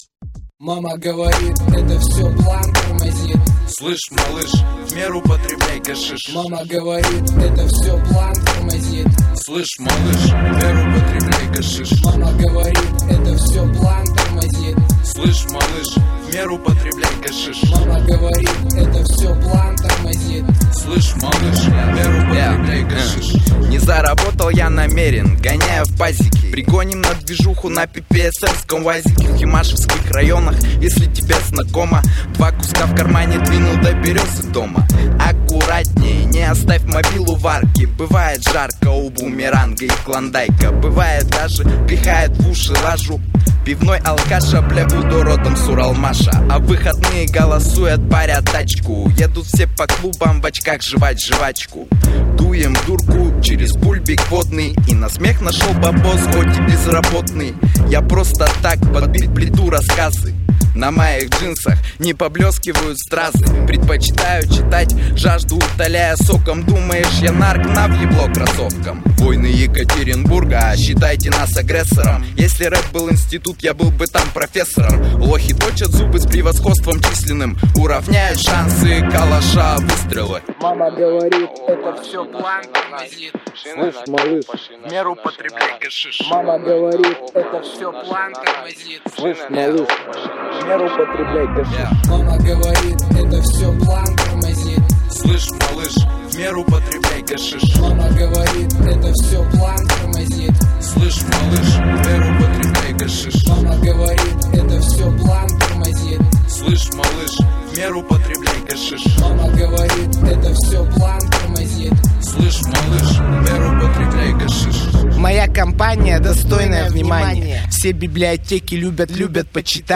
Главная » Каталог музыки » Русский рэп
Данная песня находится в музыкальном жанре Русский рэп.